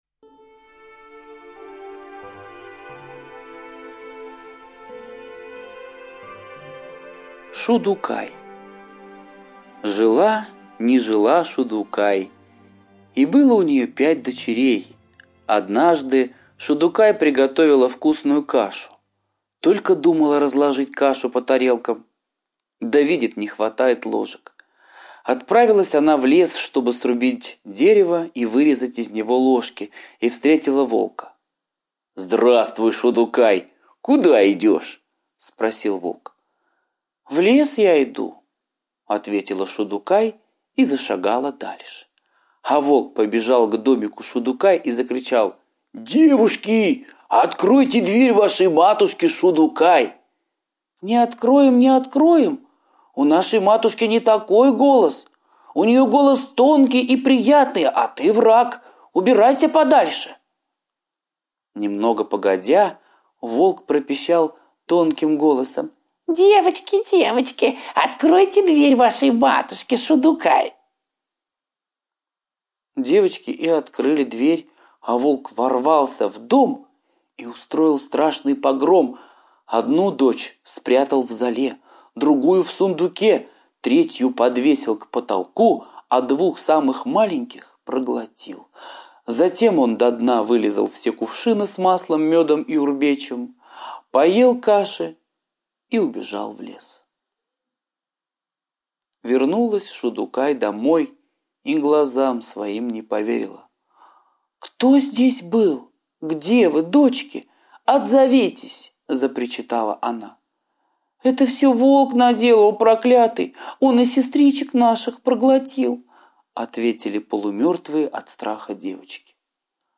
Шудукай – азиатская аудиосказка